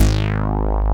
SYN_StBas G3#.wav